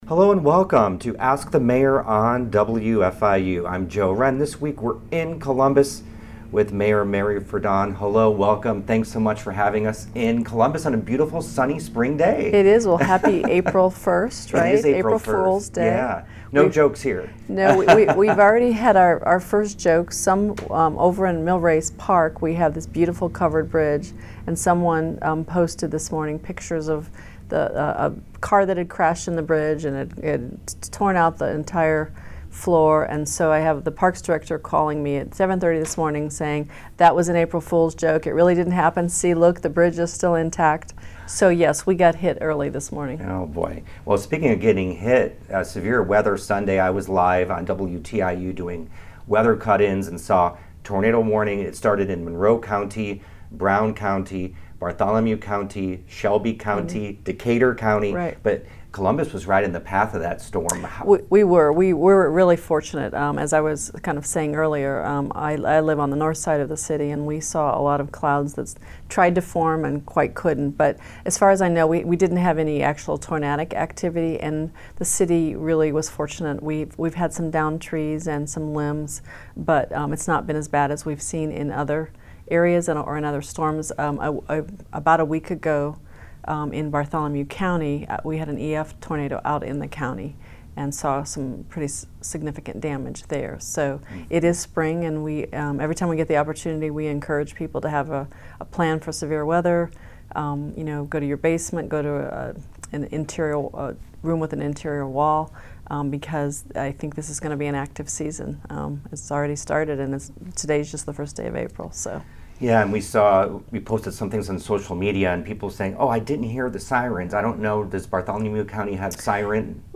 Ask The Mayor: Ferdon of Columbus on fed freezes, mixed use development, DORA